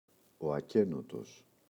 ακένωτος [aꞋkenotos]